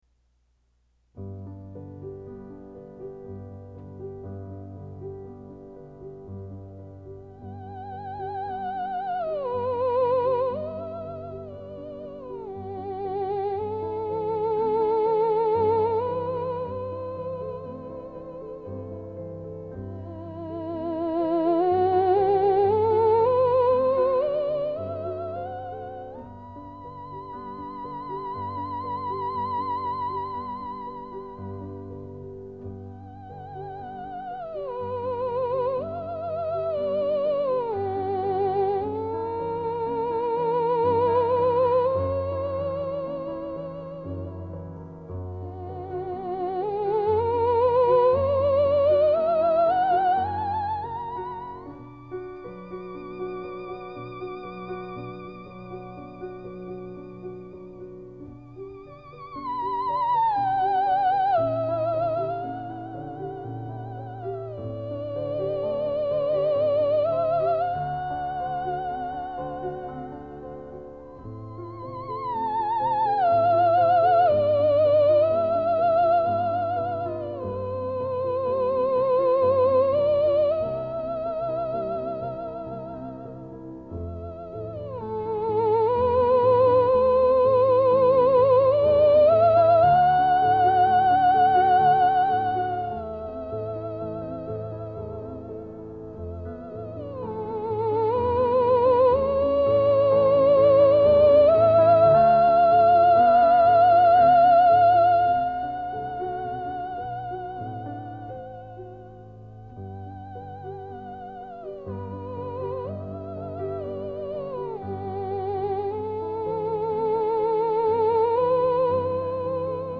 А это в тему о терменвоксе: